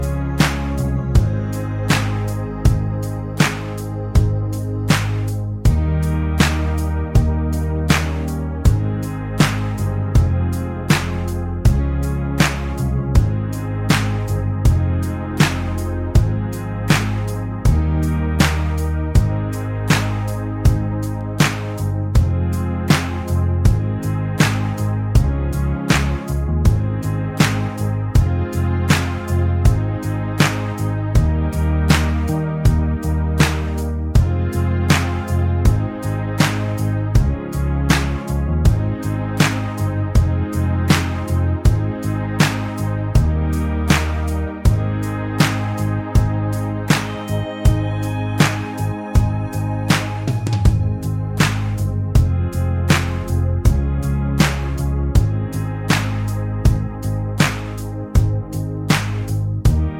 no Backing Vocals or Guitars Pop (2010s) 4:04 Buy £1.50